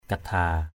/ka-d̪ʱa:/ 1.
kadha.mp3